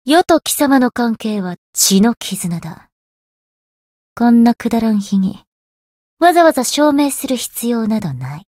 灵魂潮汐-萨缇娅-情人节（摸头语音）.ogg